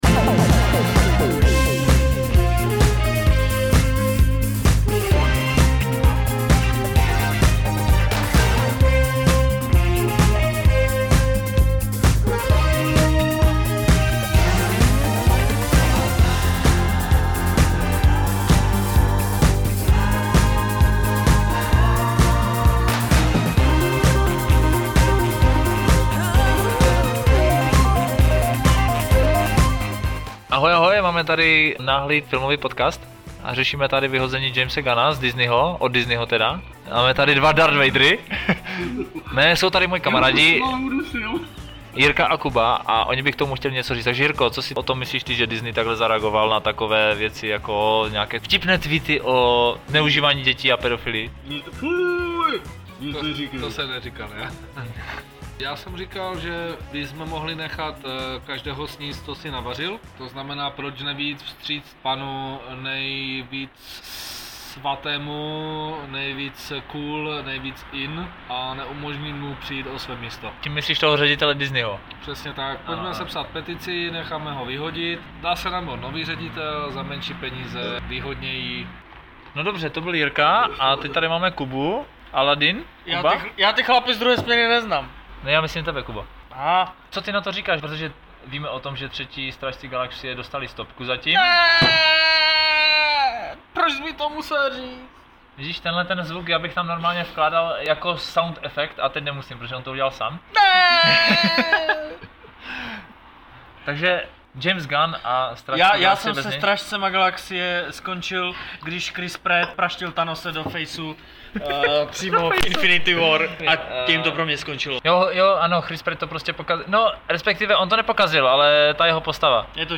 Tento Podcast je speciální v tom, že nebyl plánovaný ani připravený a vyplynul z diskuse u piva.